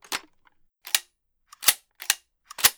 LightMachineGunLoad1.wav